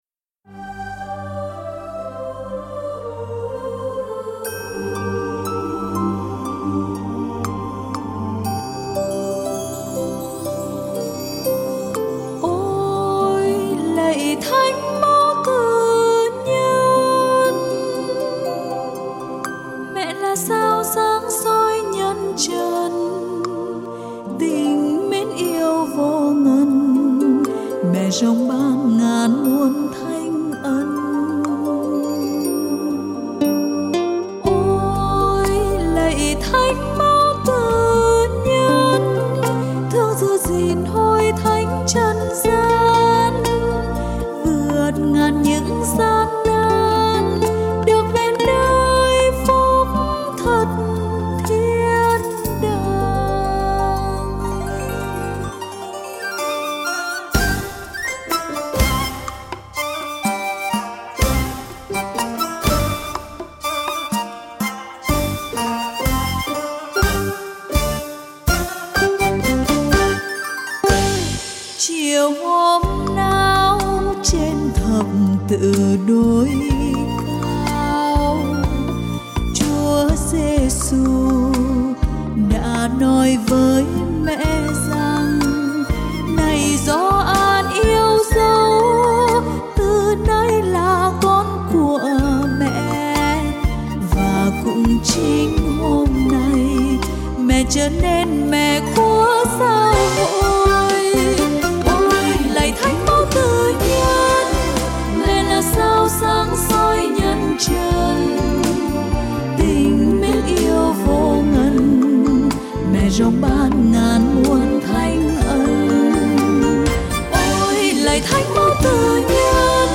Thánh ca về Đức Mẹ